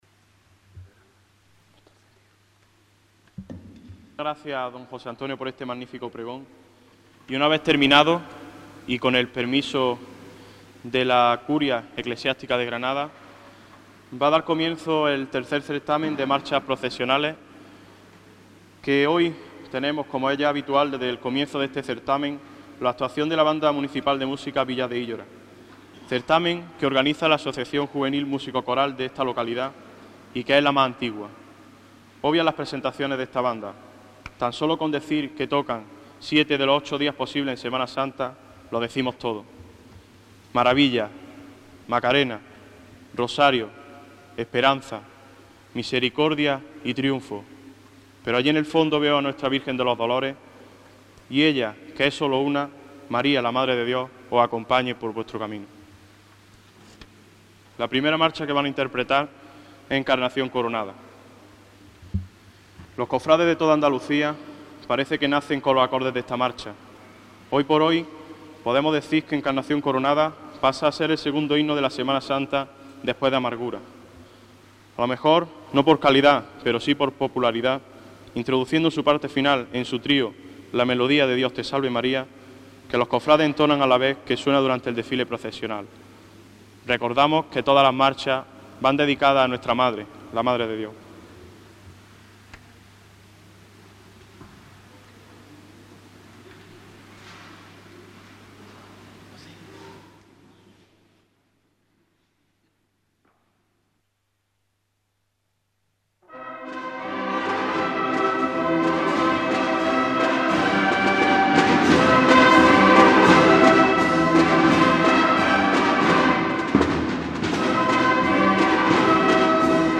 Concierto Banda de Musica Villa de Illora